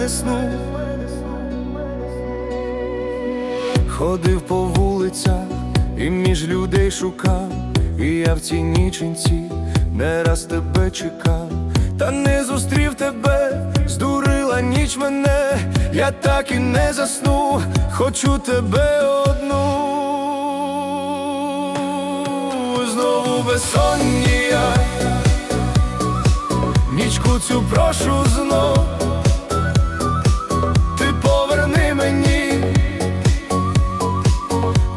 Жанр: Альтернатива / Русские